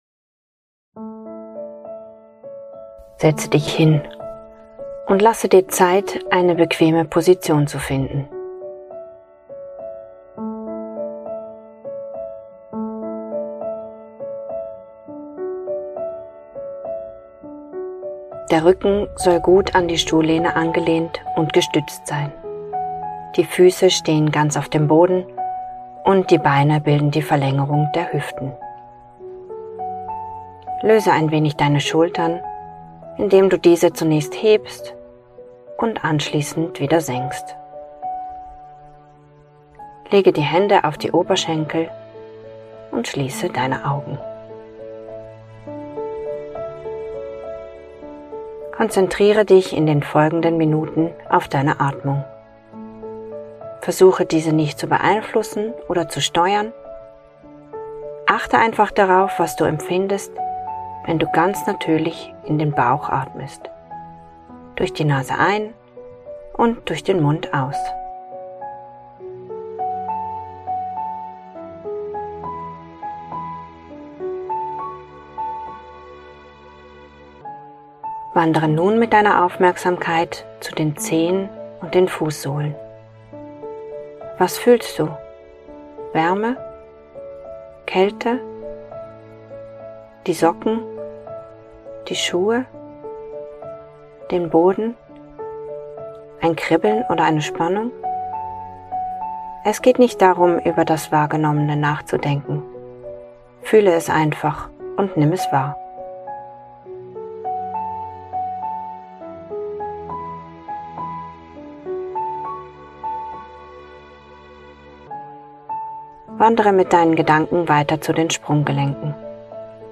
Bodyscan mit Musik